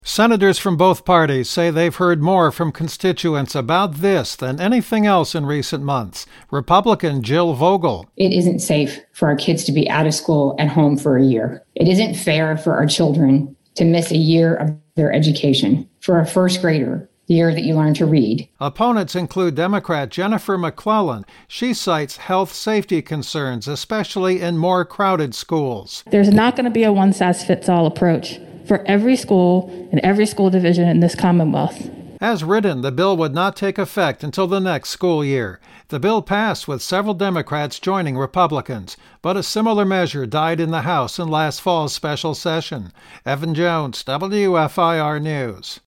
02-03-School-Bill-Wrap-WEB.mp3